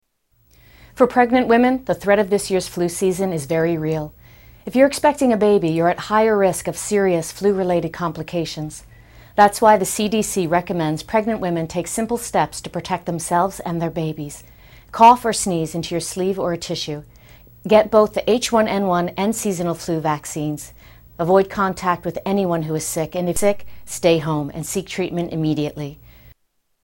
Amy Ryan PSA
Tags: Media Flu PSA's Flu Public Service Announcements H1N1